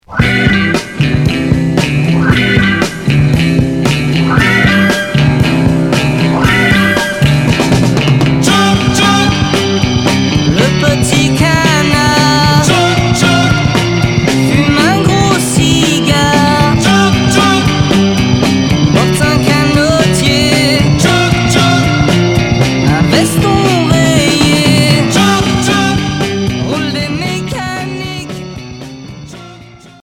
Pop rock psyché Unique 45t retour à l'accueil